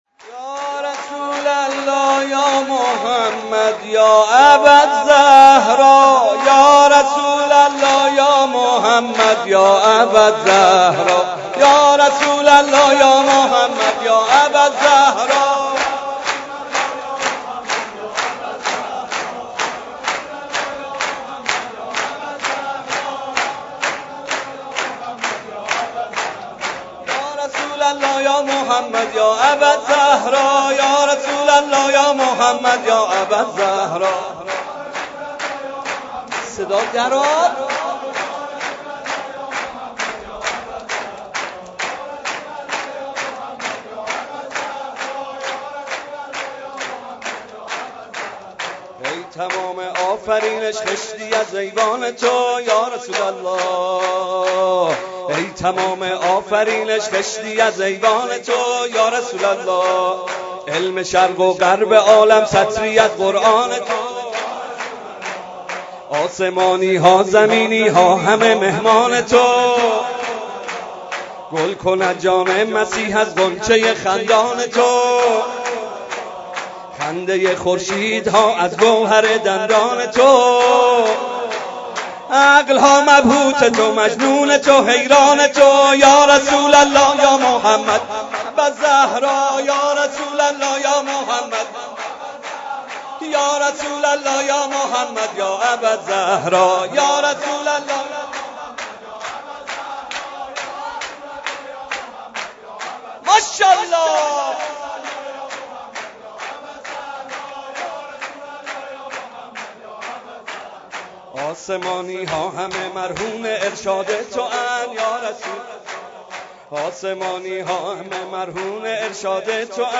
مداحی شنیدنی
به مناسبت میلاد پیامبر گرامی اسلام